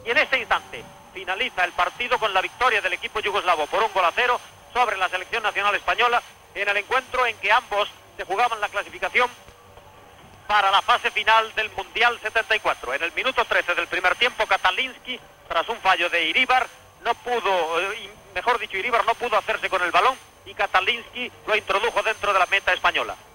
Fragment del partit Espanya 0-Iugoslàvia 1 de la fase final de clasificació pel Mundial 74 de futbol masculí
Esportiu